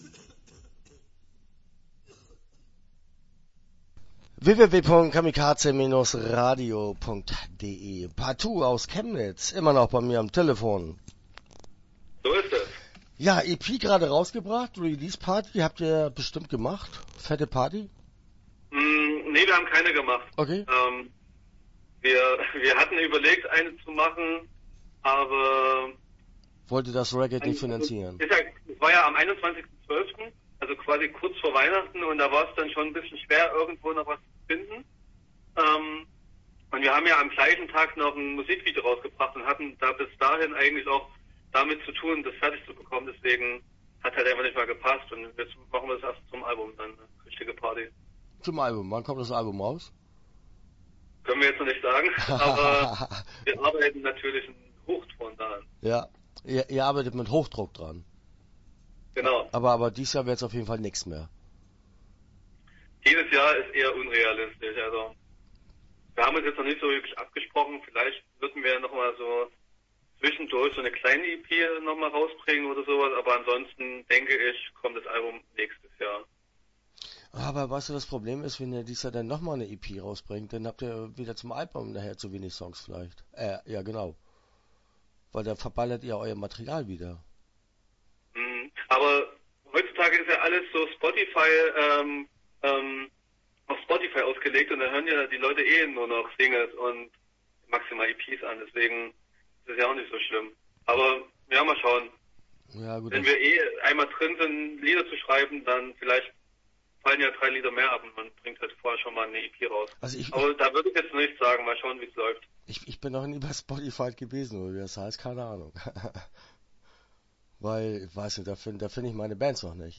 Start » Interviews » Partout